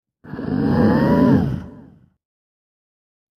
Alien Breathing; Large Creature Growl And Wheeze Breaths, Close Pov.